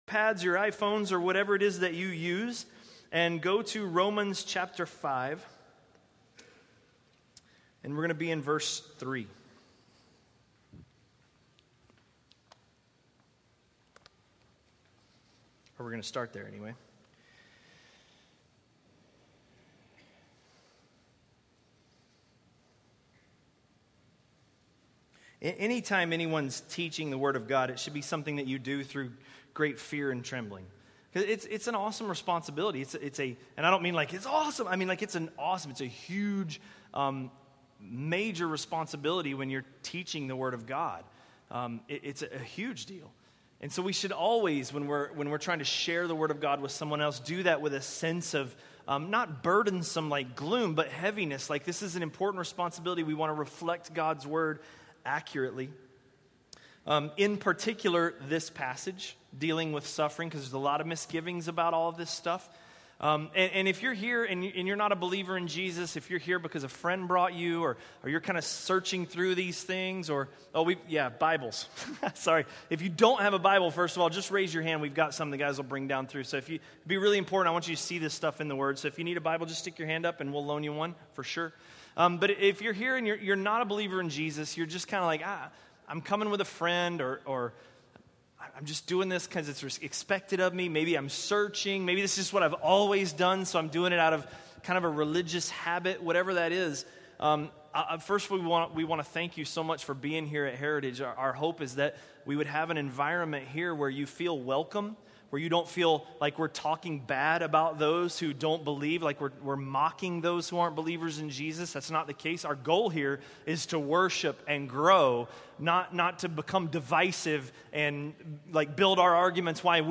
A message from the series "Romans." Romans 5:3–5:5